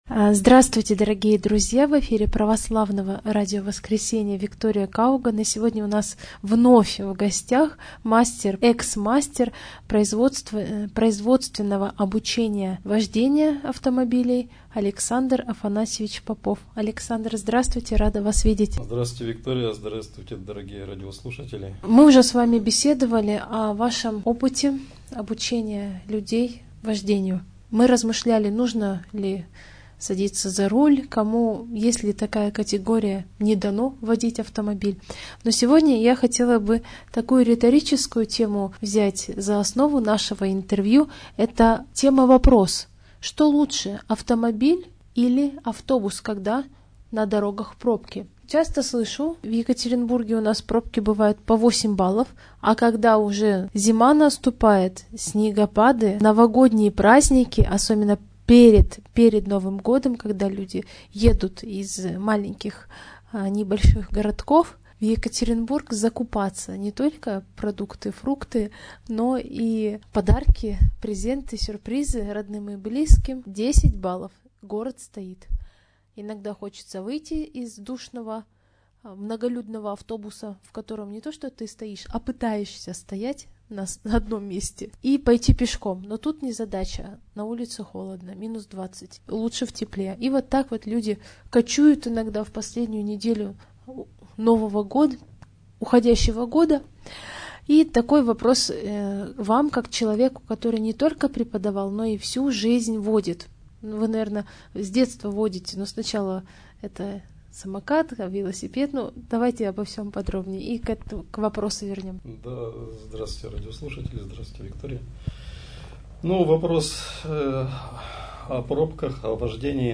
Второе интервью